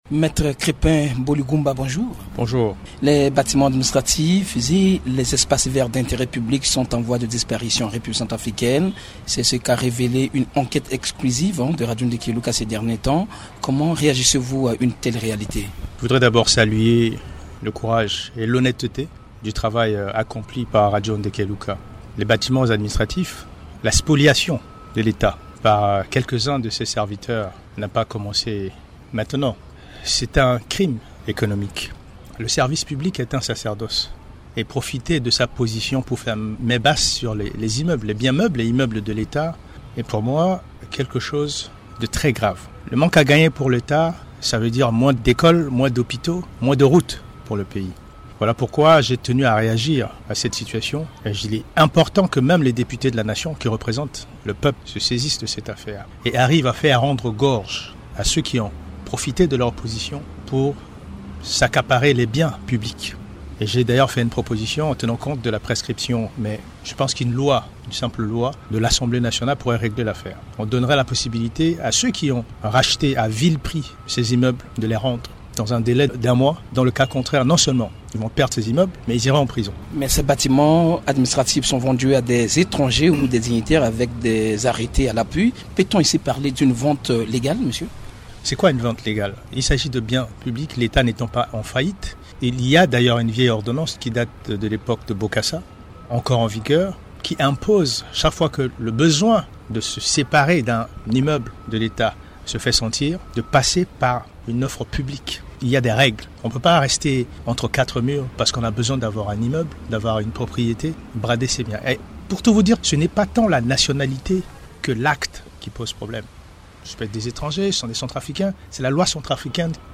Invité Maitre BOLI-GOUMBA Fr.MP3